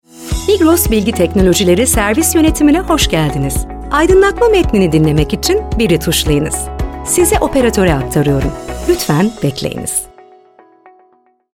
Santral Seslendirme
Santral anonslarını seslendirme yönetmeni eşliğinde, profesyonel seslendirme sanatçılarıyla ve son teknoloji ses kayıt ekipmanları ile kaydederiz.